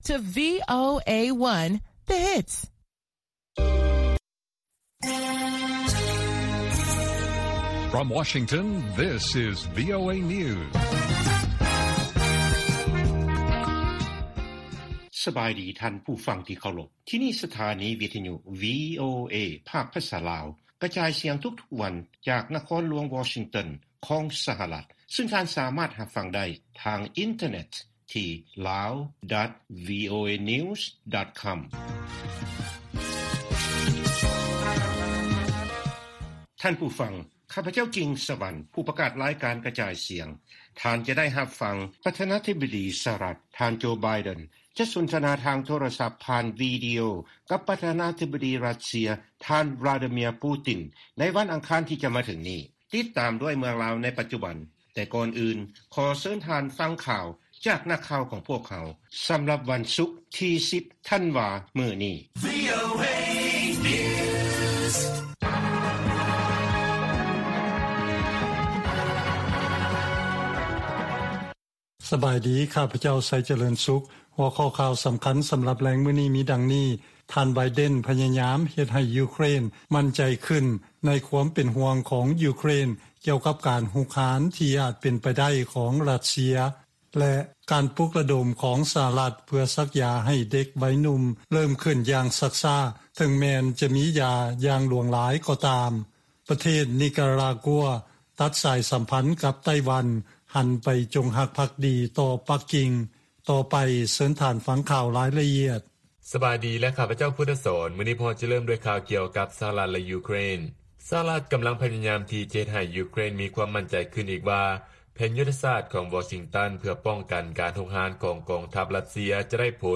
ລາຍການກະຈາຍສຽງຂອງວີໂອເອ ລາວ: ທ່ານ ໄບເດັນ ພະຍາຍາມ ເຮັດໃຫ້ ຢູເຄຣນ ໝັ້ນໃຈຂຶ້ນ ໃນຄວາມເປັນຫ່ວງຂອງ ຢູເຄຣນ ກ່ຽວກັບ ການຮຸກຮານ ທີ່ອາດເປັນໄປໄດ້ຂອງ ຣັດເຊຍ